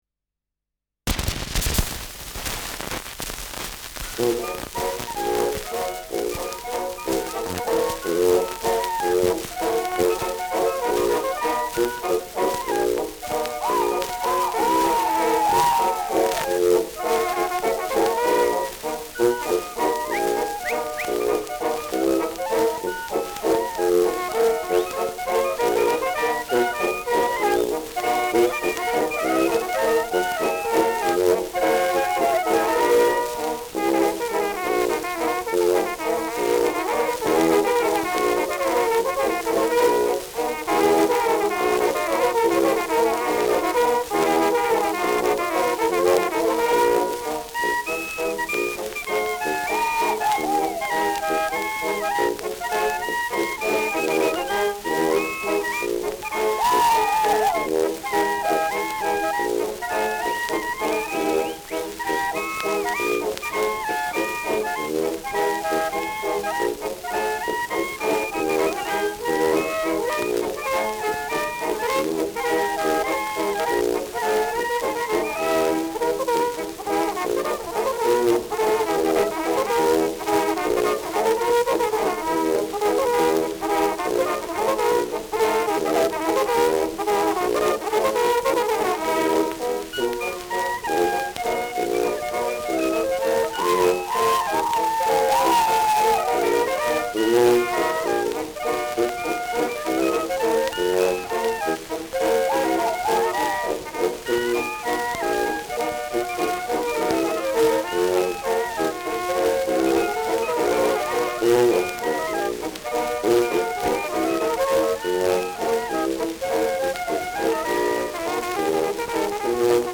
Schellackplatte
Besonders zu Beginn stark verrauscht : Gelegentlich leichtes Knacken : Nadelgeräusch
[Nürnberg] (Aufnahmeort)